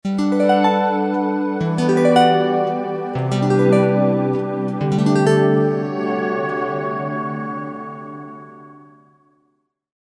Нежная мелодия на арфе для начала или романтичного момента